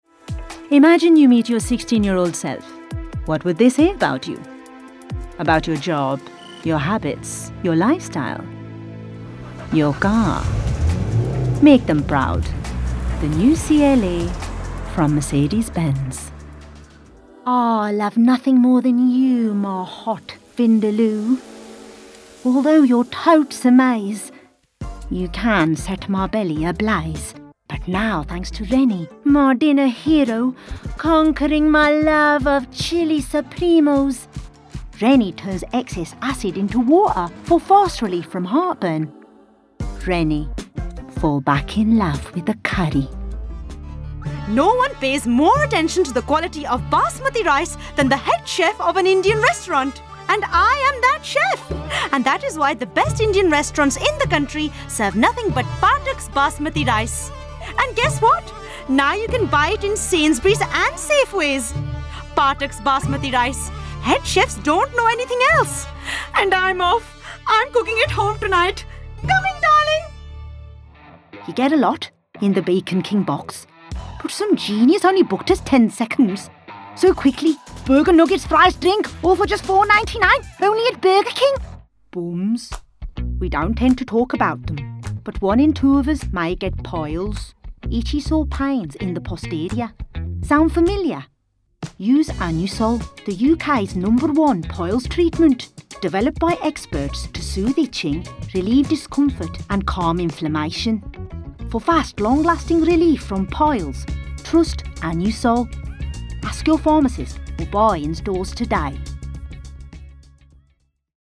40/50s, Midlands/Indian/RP,
Warm/Versatile/Experienced
Indian Accent Showreel